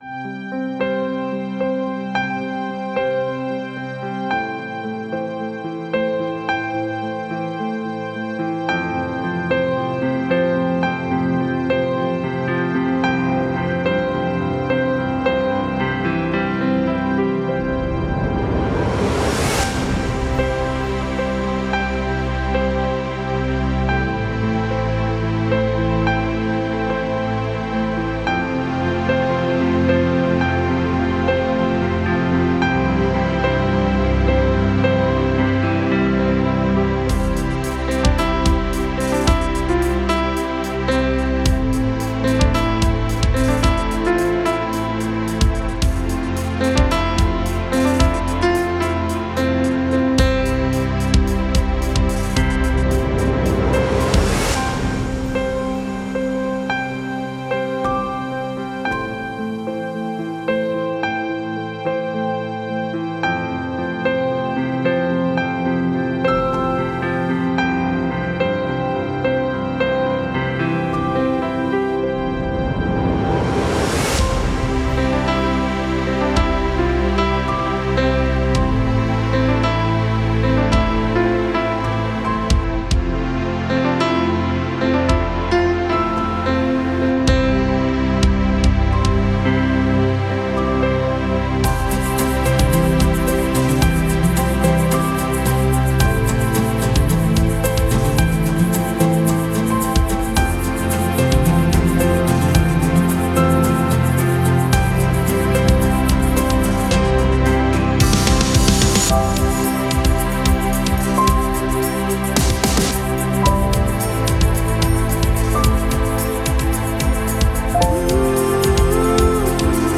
/ Pop / Rock